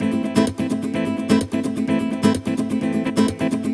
VEH3 Nylon Guitar Kit 1 - 2 A min.wav